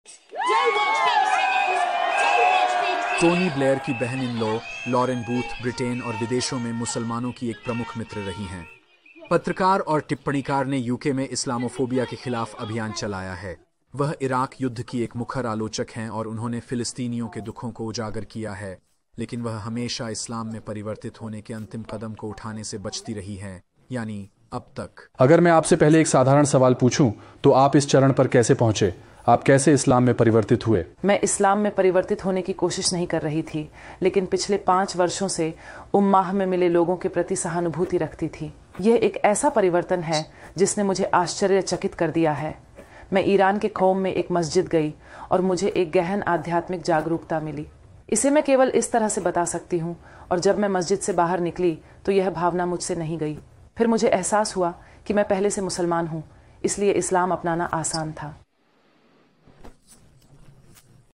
विवरण: इस वीडियो में टॉनी ब्लेयर की भाभी लॉरेन बूथ बताती हैं कि उन्होंने इस्लाम को कैसे स्वीकार किया।